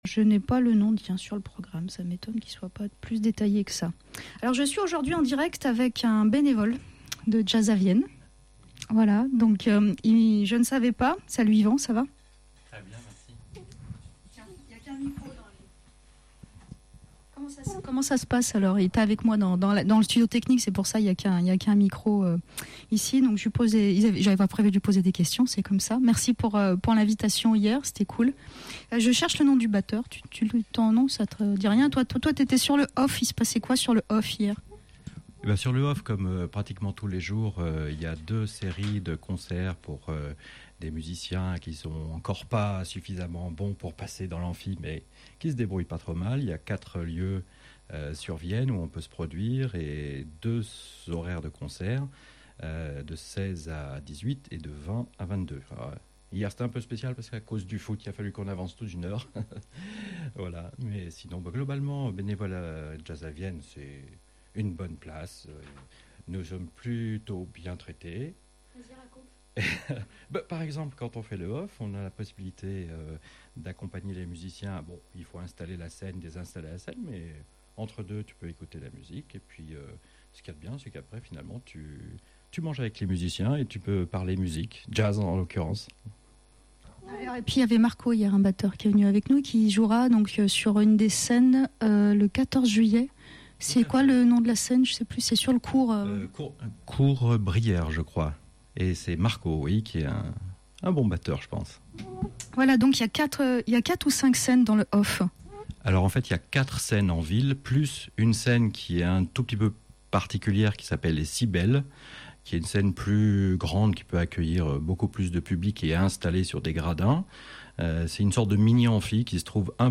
une émission patchworld spéciale jazz pour parler du peristyle de l’opera… du festival de jazz à Vienne avec en invité un bénévole